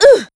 Ripine-Vox_Damage_04.wav